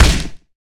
hugeLogHit2.wav